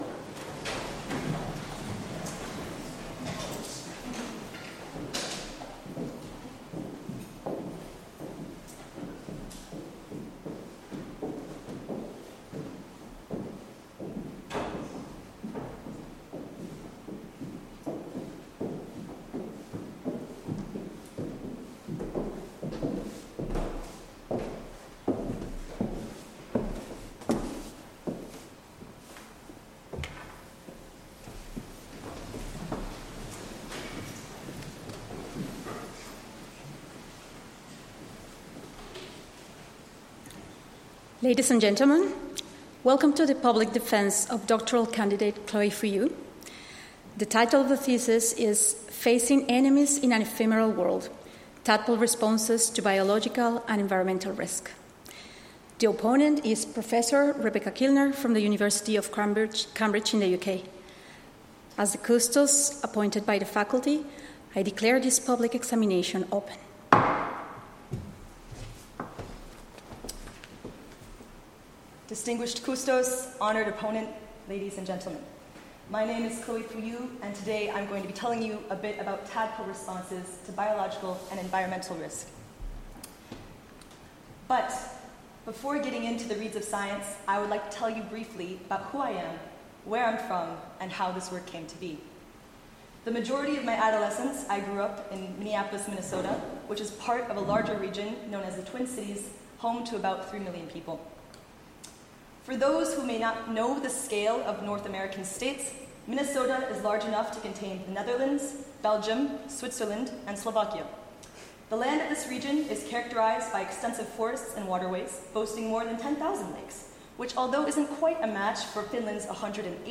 PhD defense on 11.8.2023